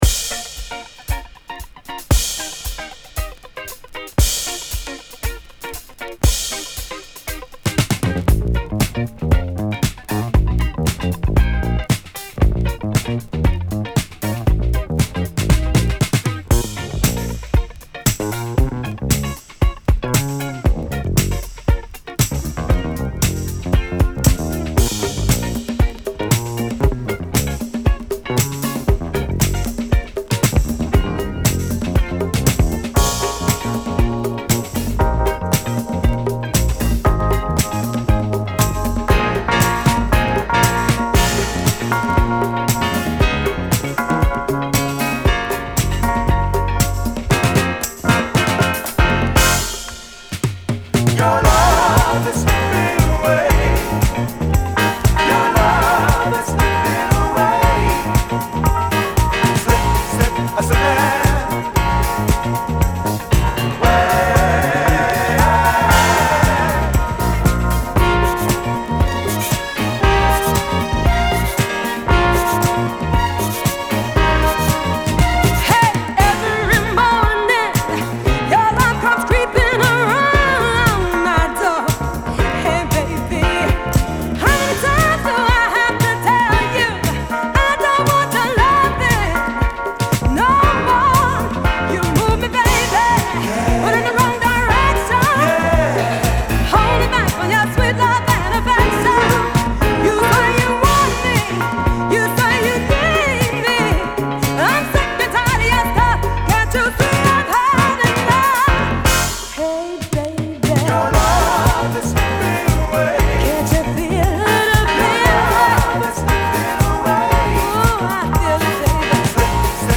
・ DISCO 70's 12'